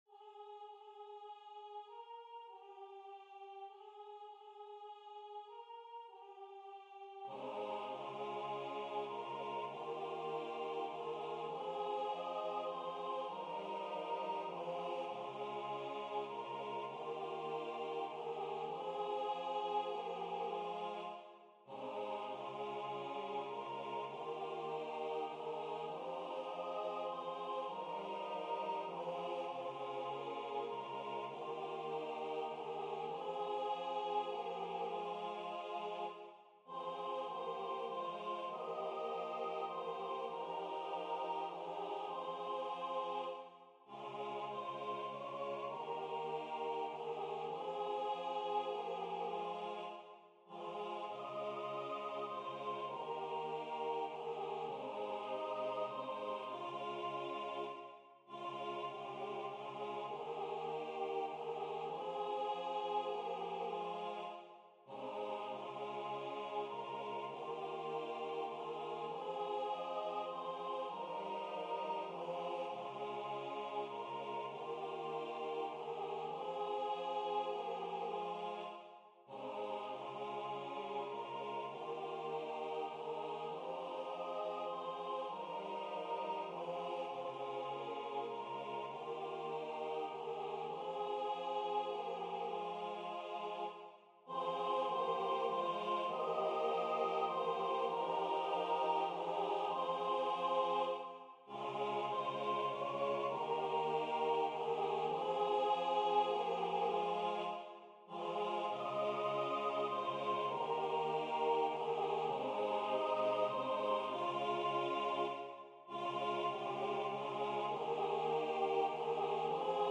- Œuvre pour chœur à 4 voix mixtes (SATB) + 1 voix soliste
Solo Voix Synth